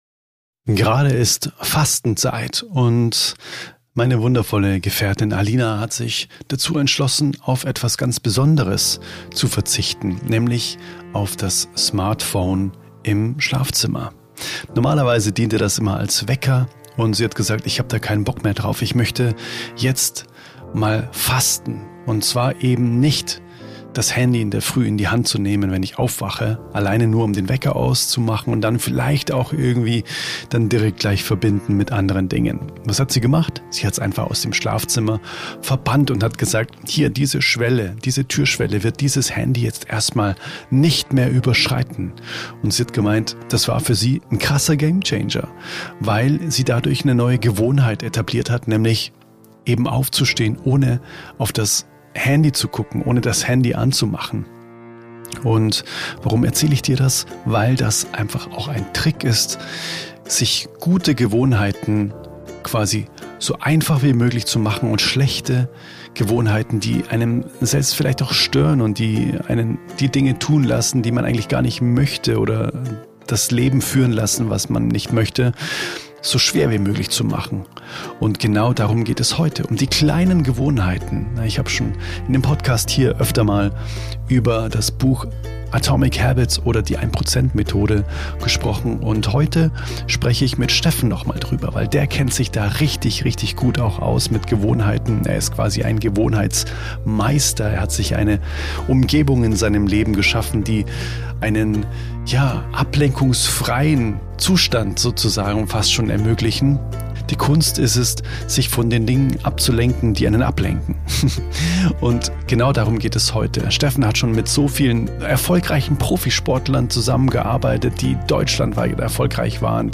[SOULTALK] Mit kleinen Gewohnheiten zu großer Freiheit | Gespräch